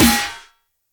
SNARE - big boy.wav